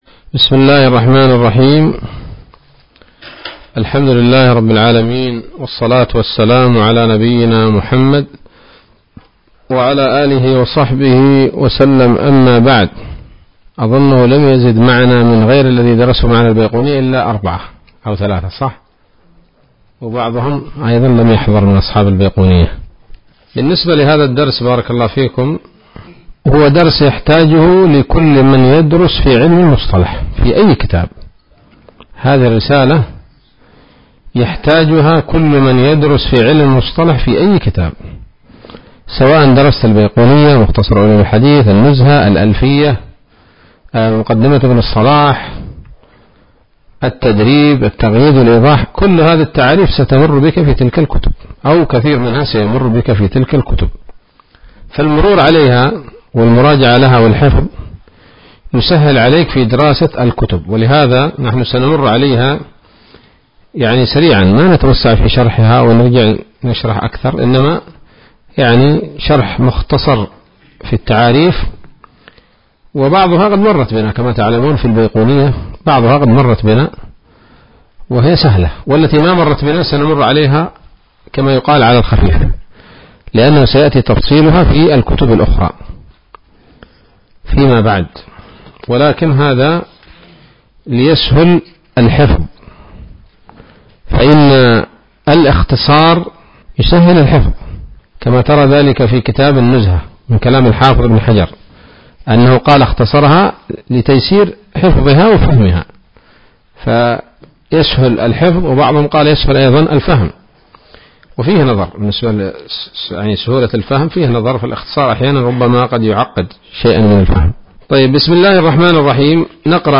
الدرس الأول من إرشاد الثِّقات إلى أهم التعريفات في علم الأحاديث النبويات